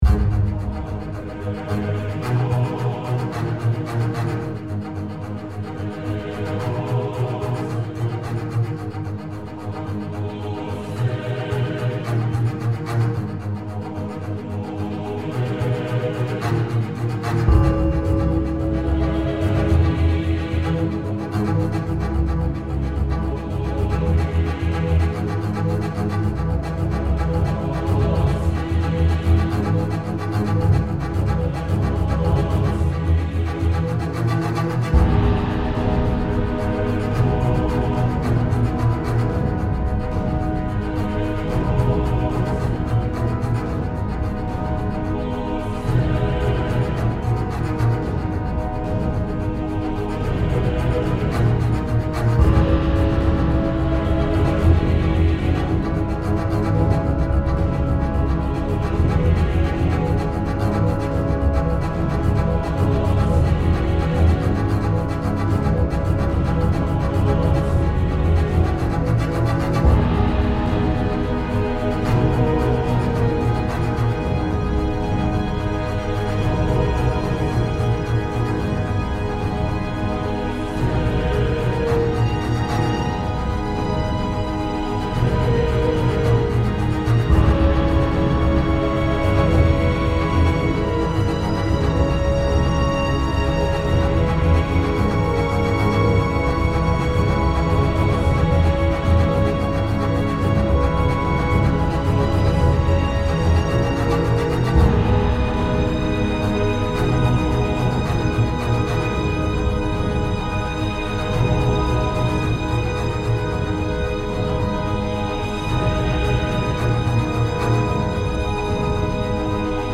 Musique dramatique